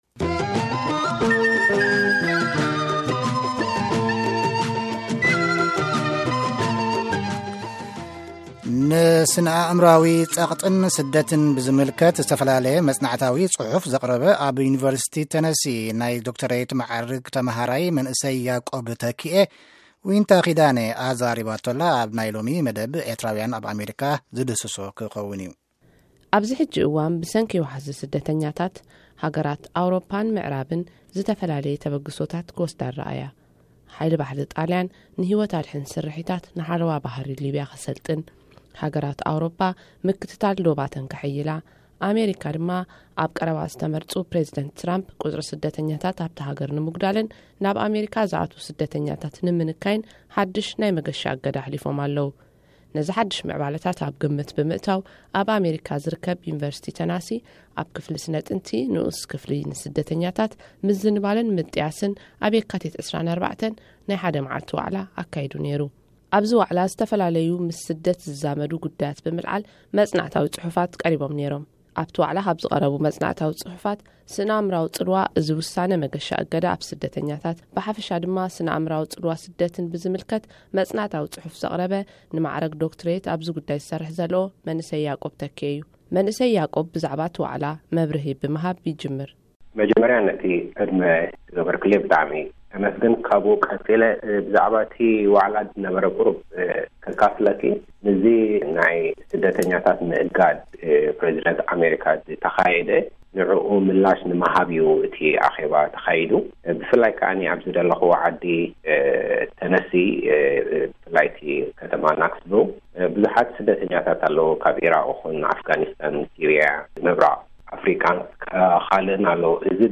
ስነ ኣእምሮኣዊ ጸቕጢን ስደትን፡ ቃለ መጠይቕ